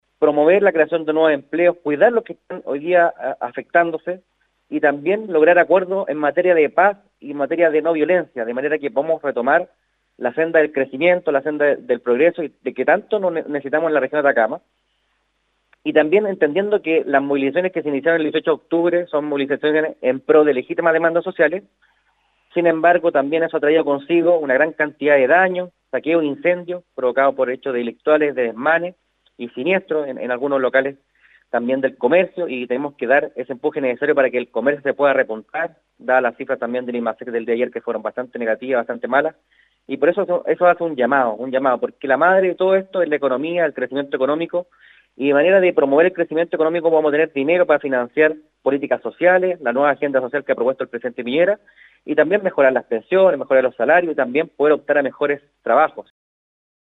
Respecto de estos anuncios, el seremi de economía de Atacama, Manuel Nanjarí, en conversación con Nostálgica explicó cómo se utilizarían estos recursos, donde 3.025 millones de dólares se destinarán a zonas afectadas, mejoramiento de espacios públicos, fortalecimiento de abastecimiento de agua, mejora en equipamiento de infraestructura educacional:
Nanjarí señaló la importancia de que la economía retome los cauces habituales, enfocándose en los conceptos de cuidar el empleo y recuperación del comercio y las pymes, esto dentro de un clima adecuado de paz ciudadana: